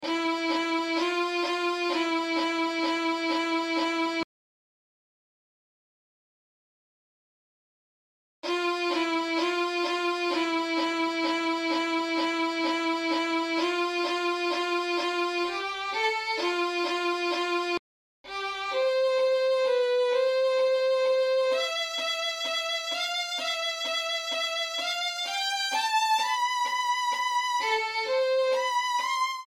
Instrumento- Violino Escala- C, 2 oitavas Nota: Os dados em falta correspondem a pausas no som.